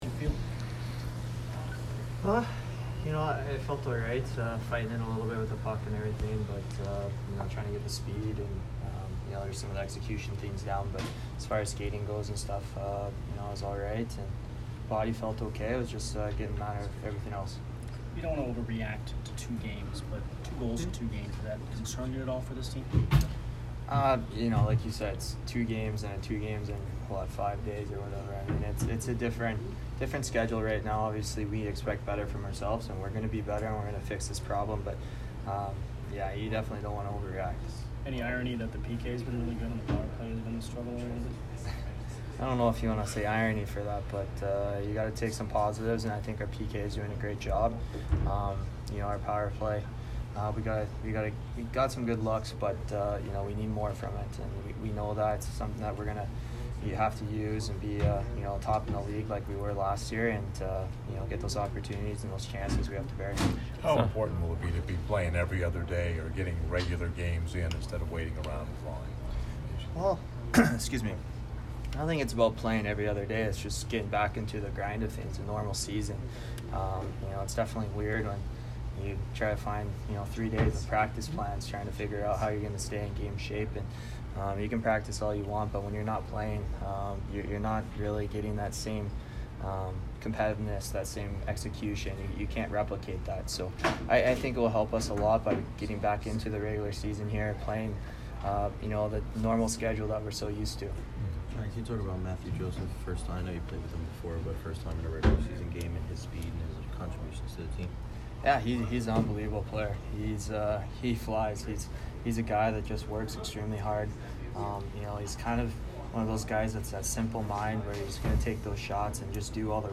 Tyler Johnson post-game 10/11